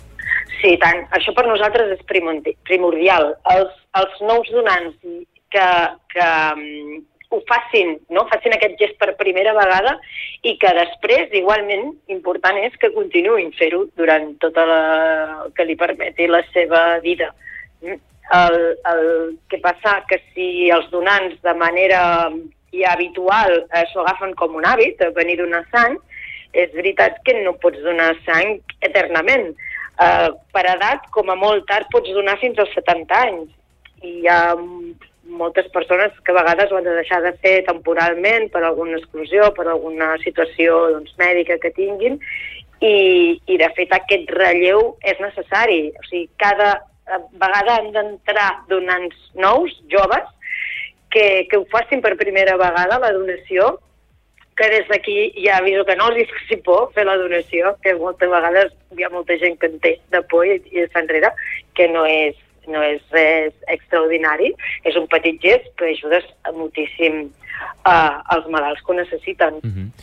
Supermatí - entrevistes